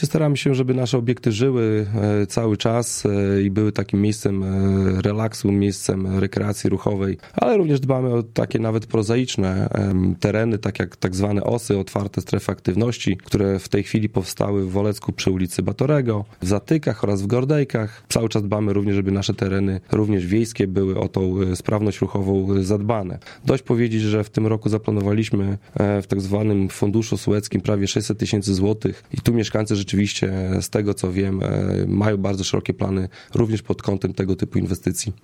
Mijający 2019 rok podsumował w piątek (27.12) na antenie Radia 5 Karol Sobczak, burmistrz Olecka.